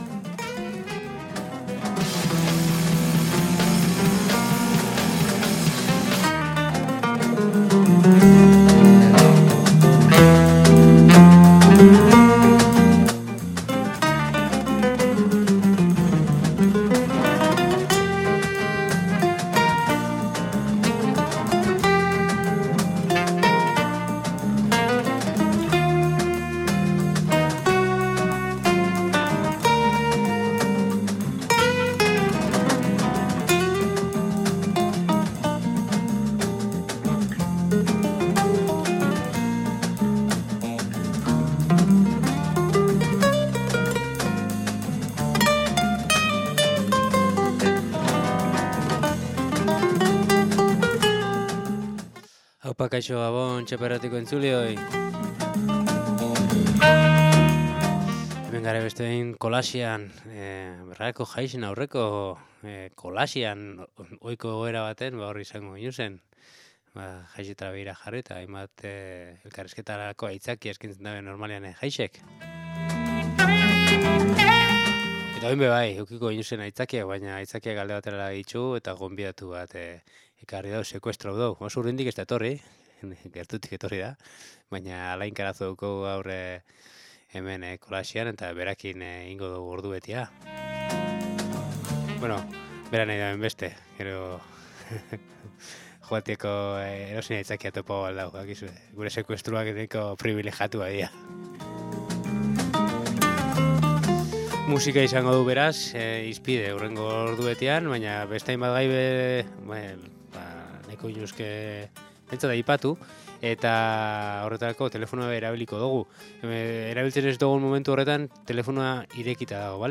Elementu ezberdinez osatutako kolax radiofonikoak, gai sozialak, kulturalak… jorratzen ditu. Kolaxa sortzeko elkarrizketak, albisteak eta kolaboratzaile ezberdinen ahotsak tartekatzen ditu. 15ean behin ordubete.